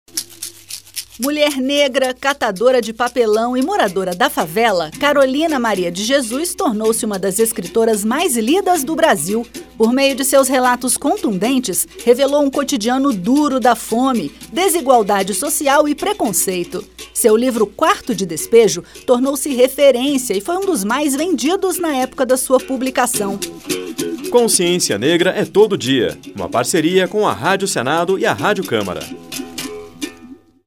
A Rádio Senado e a Rádio Câmara lançam uma série de cinco spots que homenageiam personalidades negras que marcaram a história do Brasil, como Abdias Nascimento, Carolina Maria de Jesus, Dragão do Mar, Maria Firmina dos Reis e Luiz Gama.